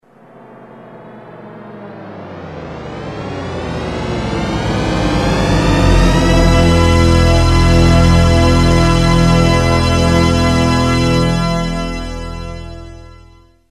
Dolby-Digital-Test-Sounds-Car-Horn.mp3